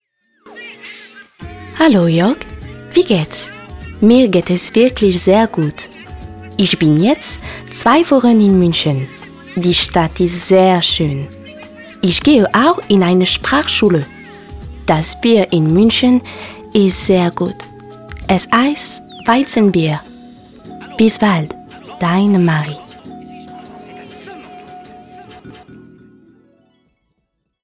Sprechprobe: Industrie (Muttersprache):
french female professional voice over , I gave my voice for several projects : E.learning, TV spot , Jingles, Commentary, Audioguides, Voiceover ...